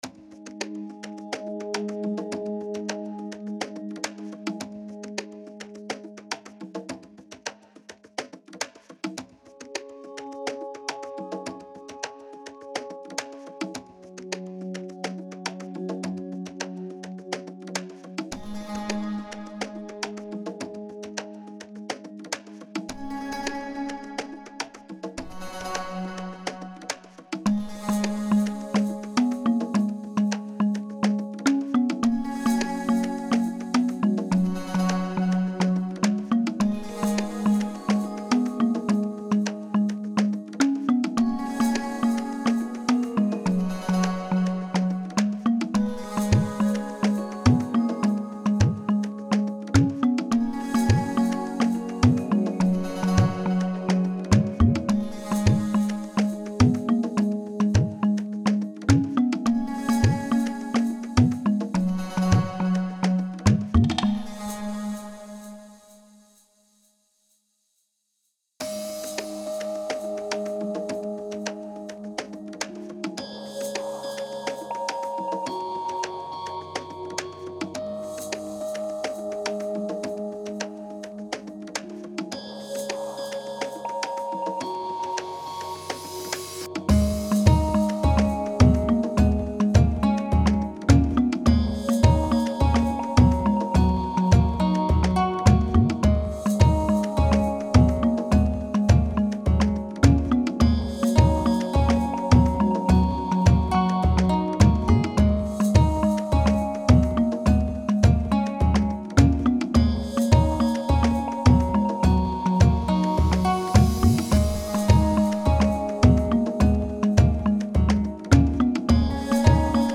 I see it as a night jungle or a night forest, for example.